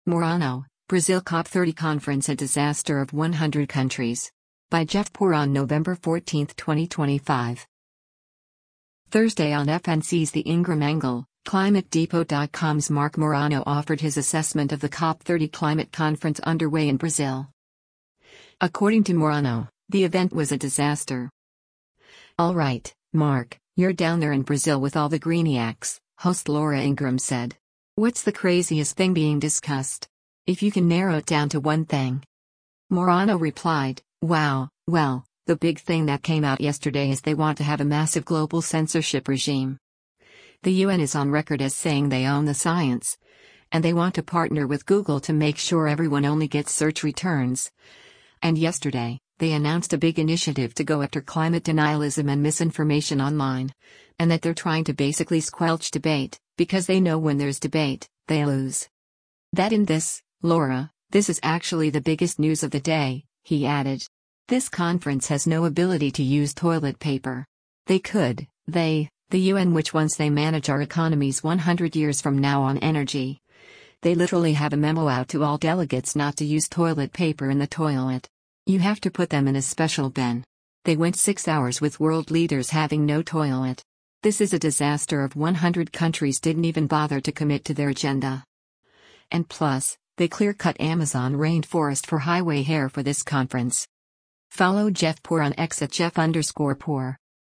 ” host Laura Ingraham said.